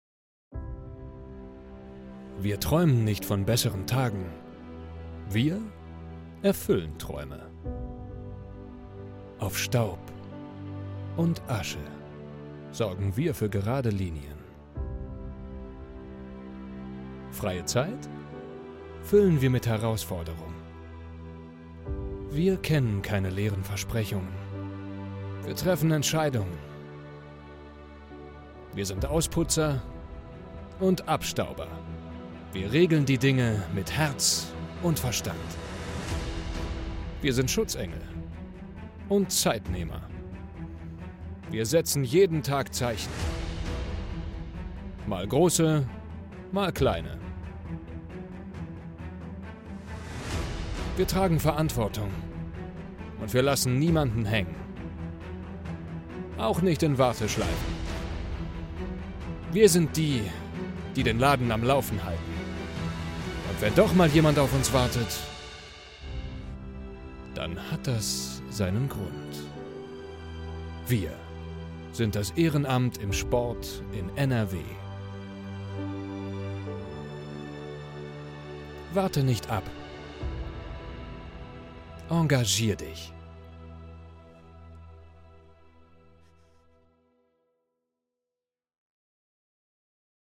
hoher Bariton / mittel - minus , etwa 25 - 40 hell, freundlich, klar, frisch, einfühlsam, emotional, informativ, variabel einsatzbar
Sprechprobe: Industrie (Muttersprache):